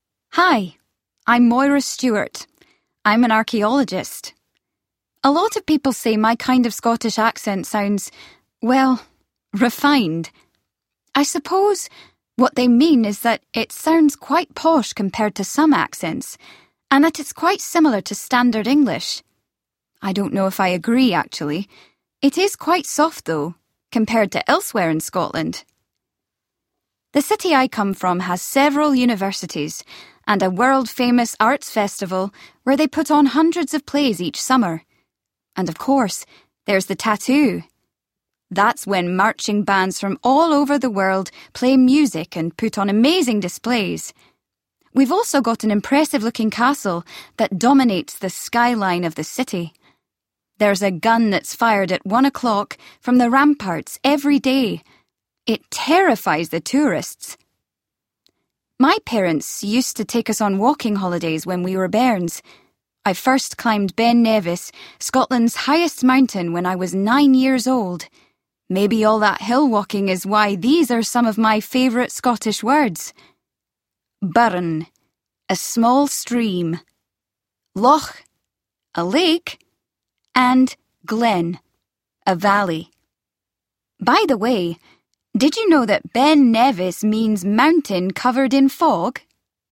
Scottish English
Can you hear a difference in their accents?